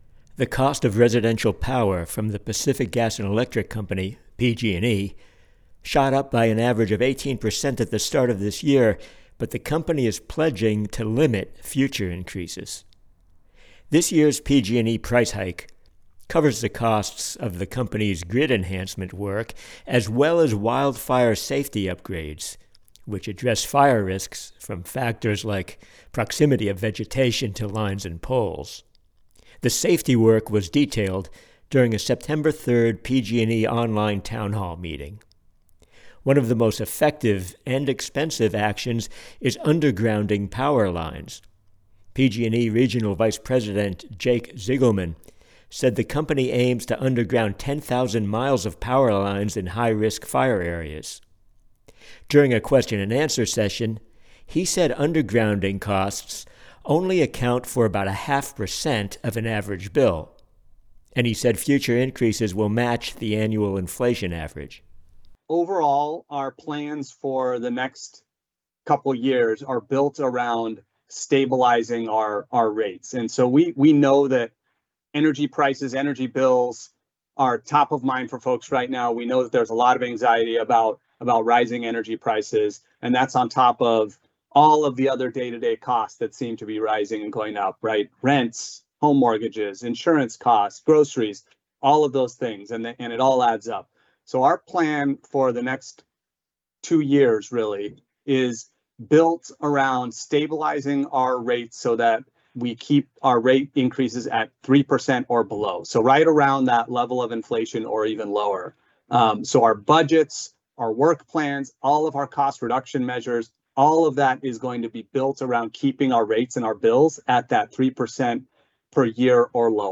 During a town hall event on wildfire safety work, a representative of PG&E promised that future annual rate increases will be no more than the standard inflation rate of three percent.